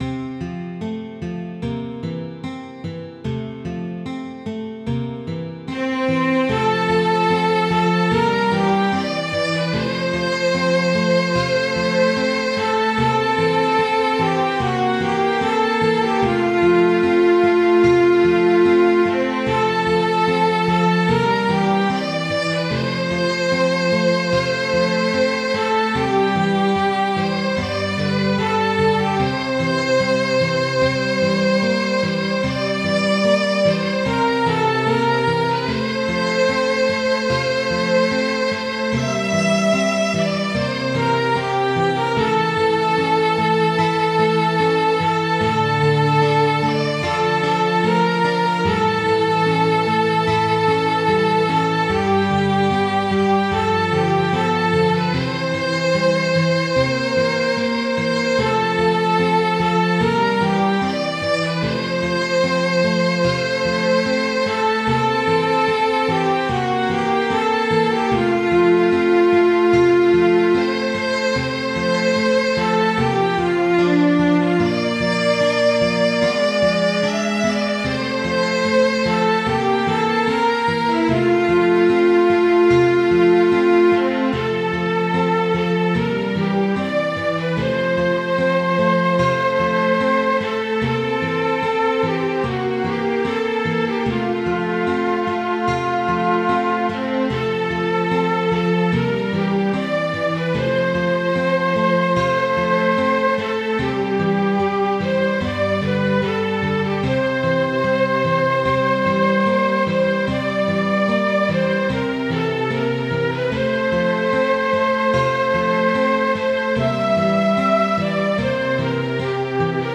Midi File, Lyrics and Information to I'll Take You Home Again, Kathleen